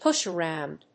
púsh aróund